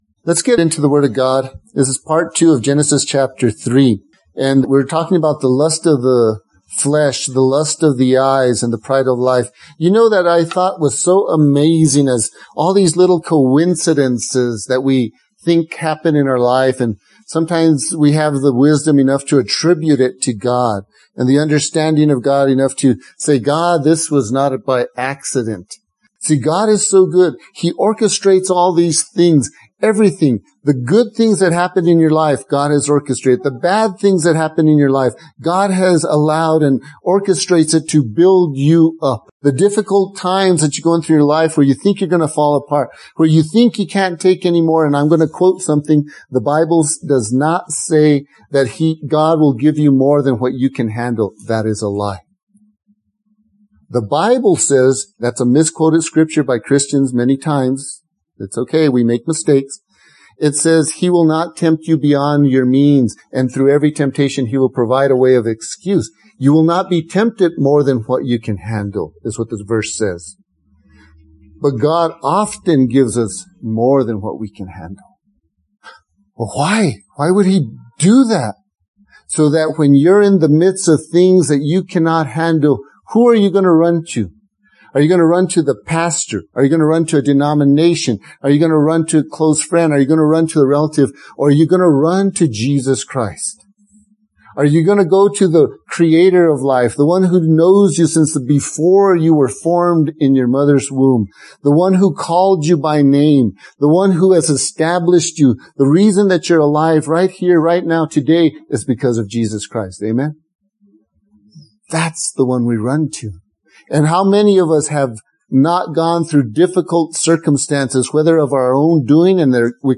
Sermons - Sonlit Hills Christian Fellowship